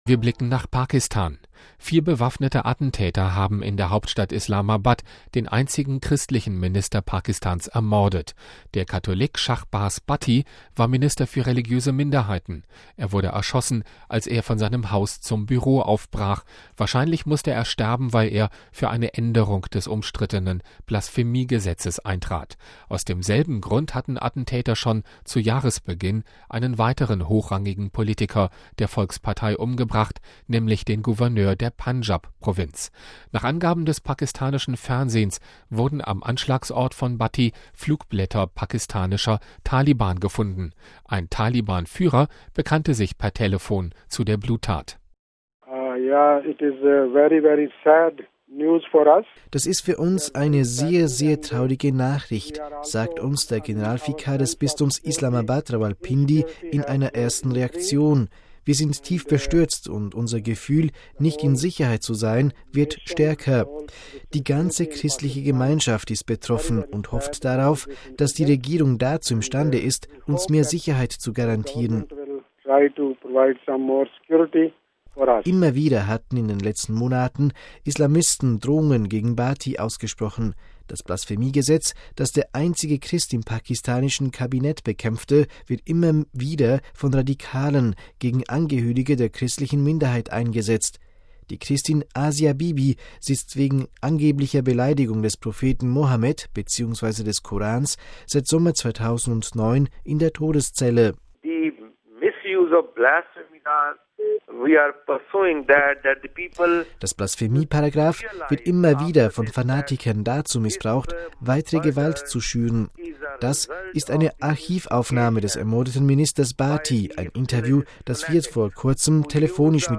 Das ist eine Archivaufnahme des ermordeten Ministers Bhatti – ein Interview, das wir vor kurzem telefonisch mit ihm führten.